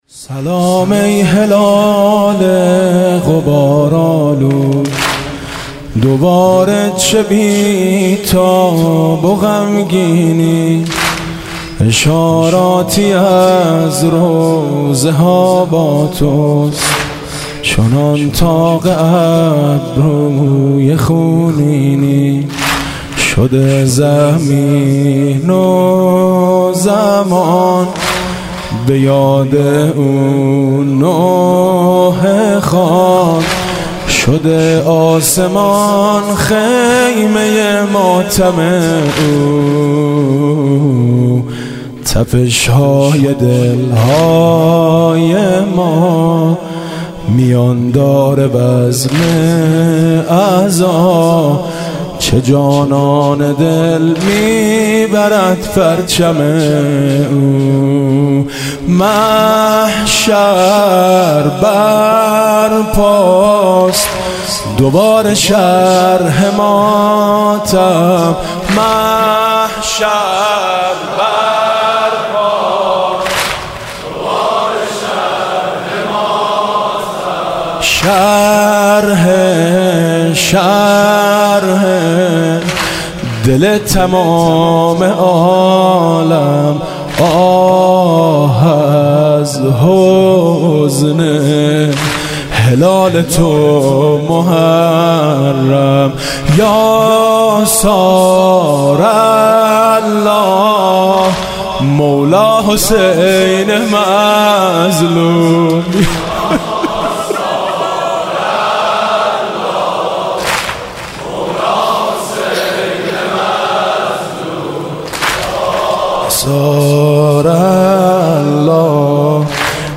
• دانلود نوحه و مداحی